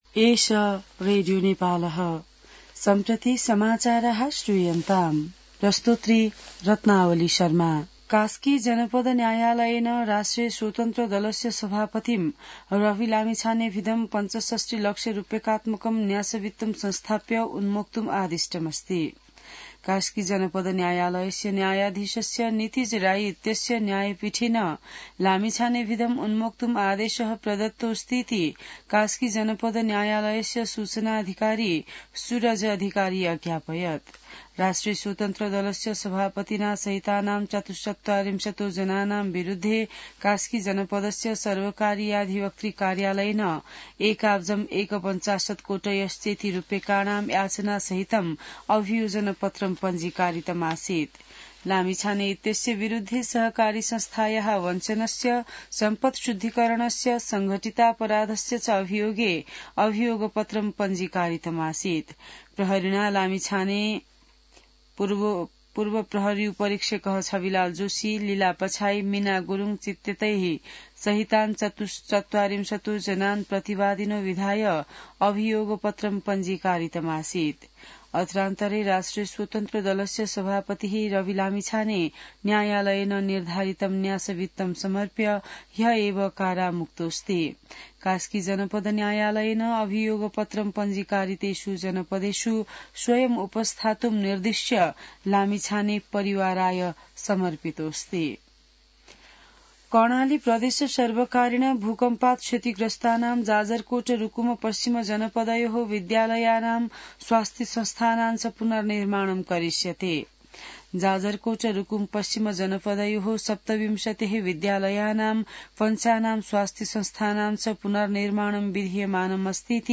An online outlet of Nepal's national radio broadcaster
संस्कृत समाचार : २७ पुष , २०८१